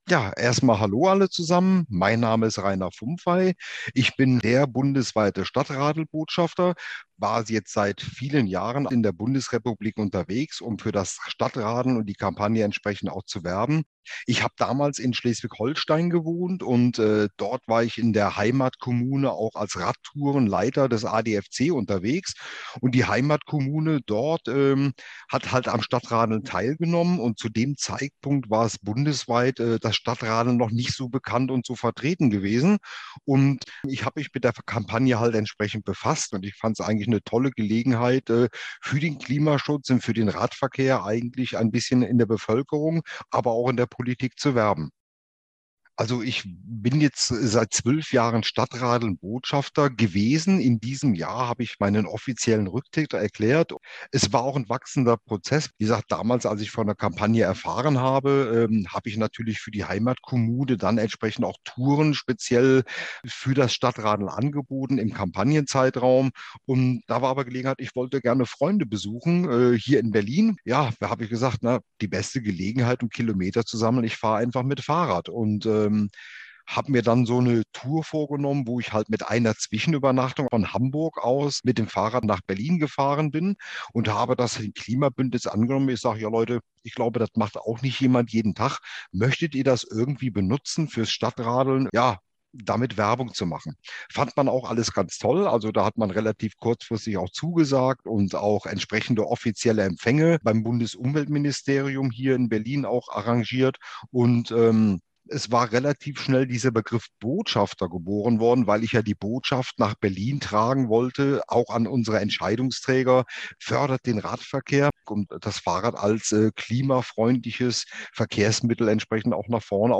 Im Interview erzählt er, wie er STADTRADELN-Botschafter geworden ist, was ihm daran gefällt und warum er das Fahrradfahren so liebt.